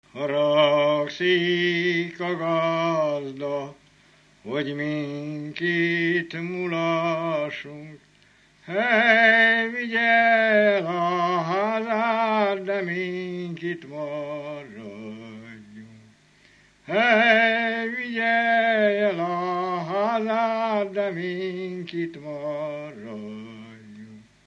Felföld - Zemplén vm. - Nagyrozvágy
ének
Műfaj: Lakodalmas
Stílus: 5. Rákóczi dallamkör és fríg környezete
Kadencia: 4 (4) 4 1